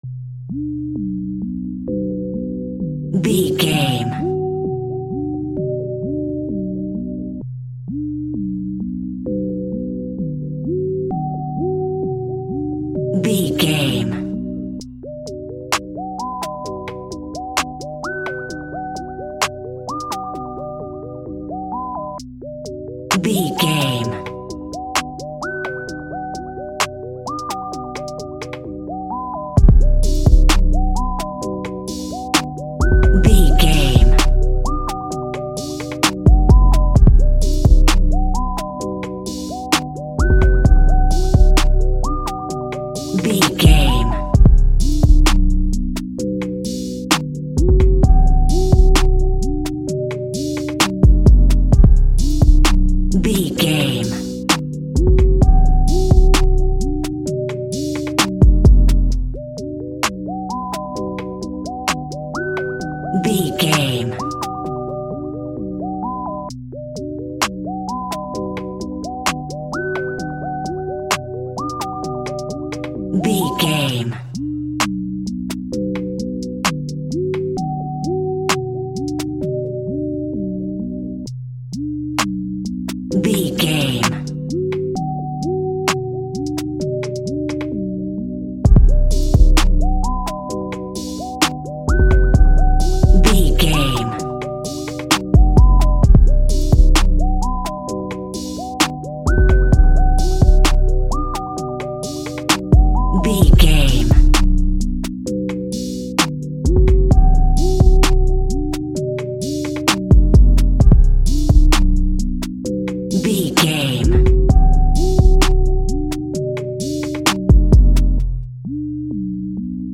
Ionian/Major
aggressive
intense
driving
dramatic
energetic
dark
drums